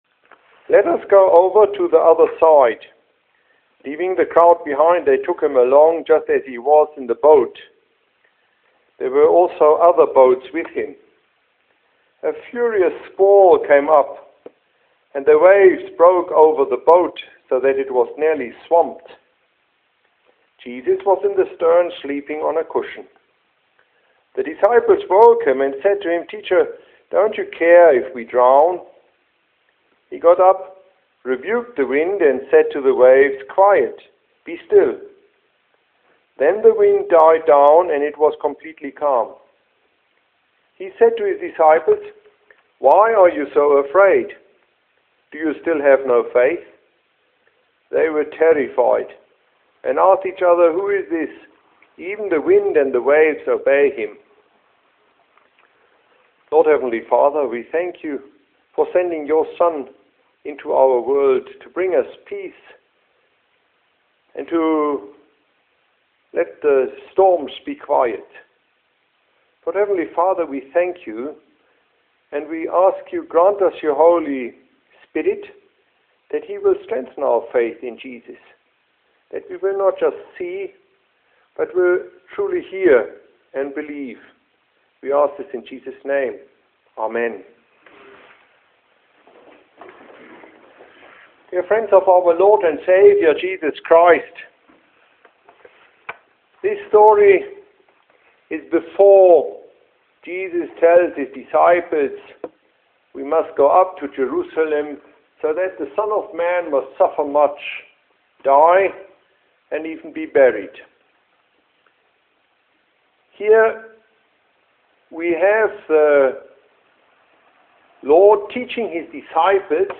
Sermonette during Matins
mk-435ff-matins.mp3